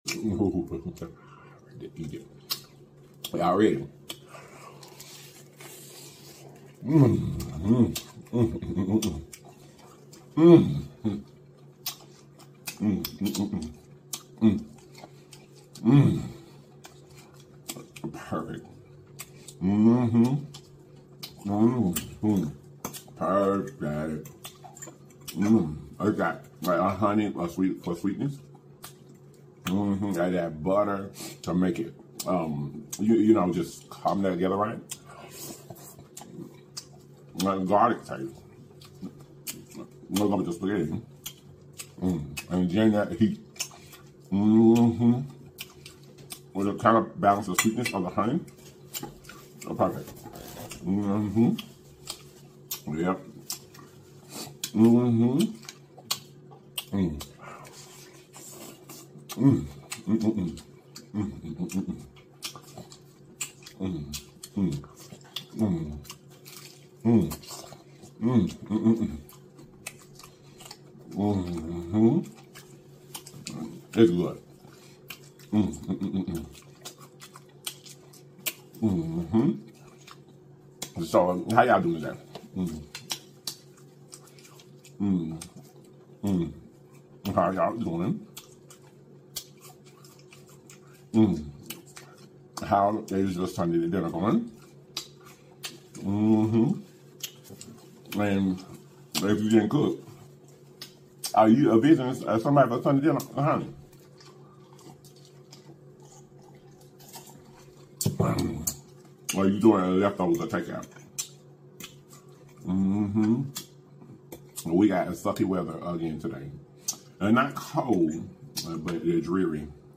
SPICY HONEY BUTTER GARLIC FRIED CHICKEN SOULFOOD MUKBANG